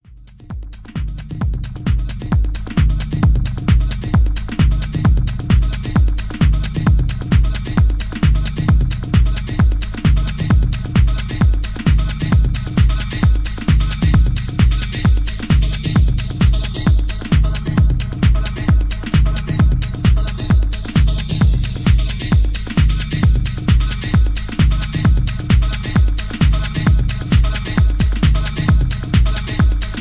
Positive dynamic techno house track